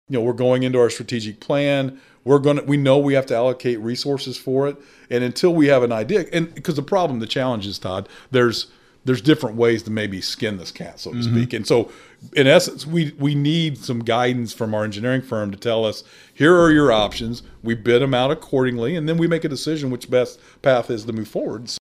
Speaking on our podcast “Talking about Vandalia,” Mayor Doug Knebel says he has committed to getting this taken care of—they just need more solid information on what they are looking at from a cost standpoint.